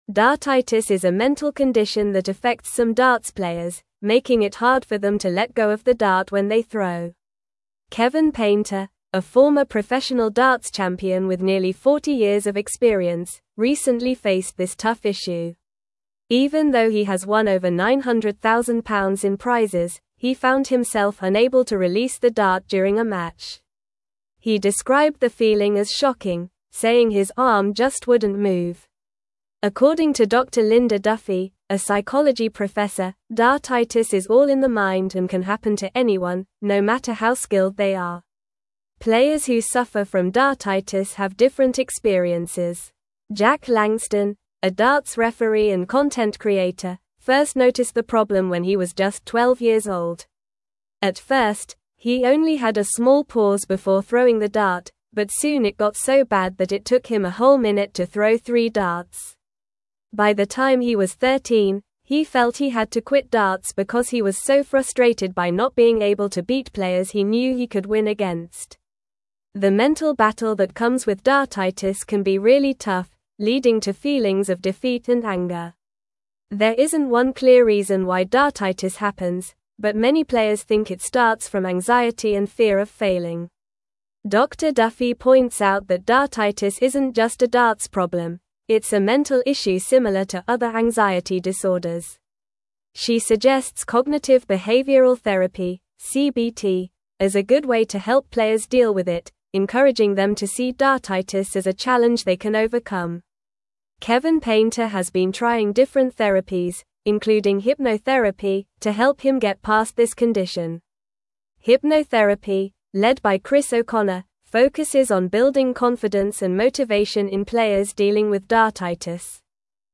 Normal
English-Newsroom-Upper-Intermediate-NORMAL-Reading-Overcoming-Dartitis-Athletes-Mental-Health-Challenges.mp3